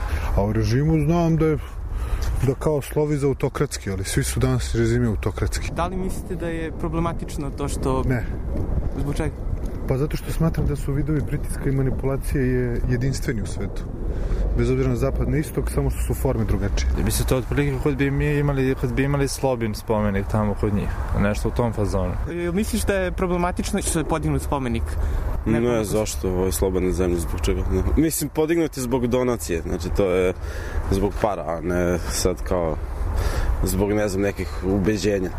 Tašmajdanski park u centru Beograda.
Neke od njih pitali smo koliko znaju o azerbejdžanskom režimu i tamošnjoj situaciji: